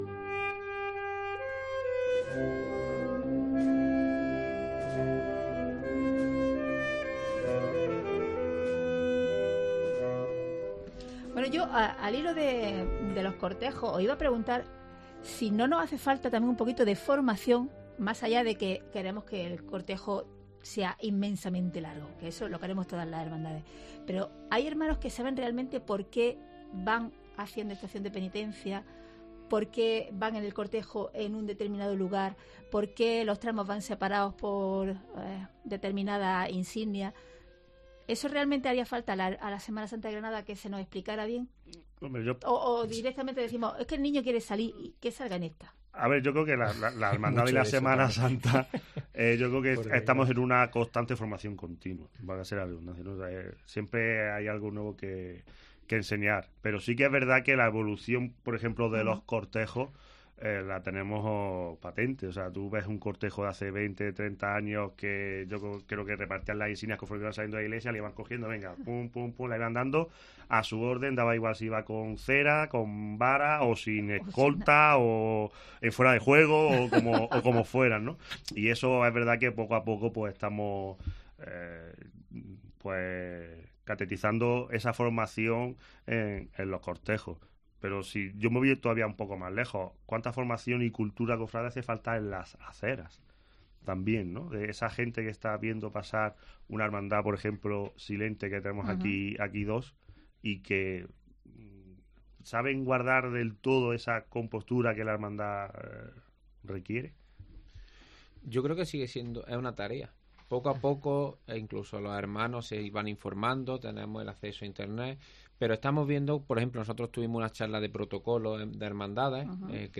AUDIO: Una charla con cuatro hermanos mayores nos lleva a diseñar cómo podría ser la Semana Santa del futuro